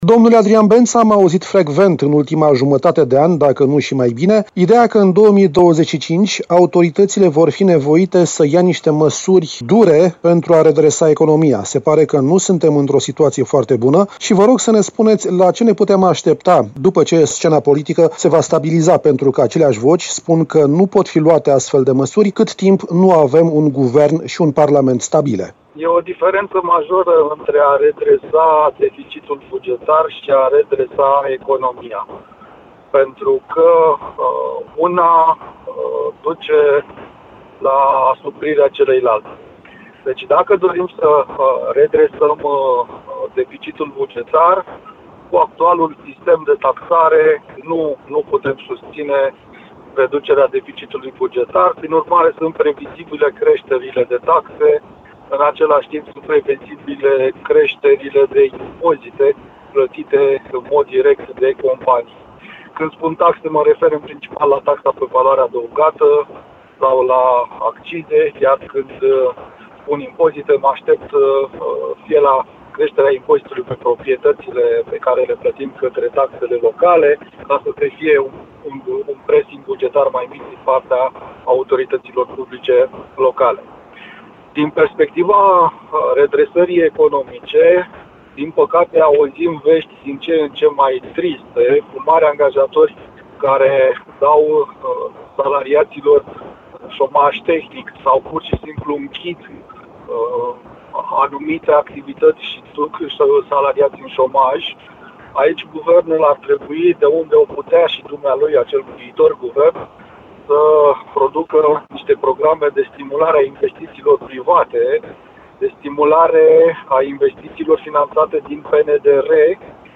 "interviul dimineții"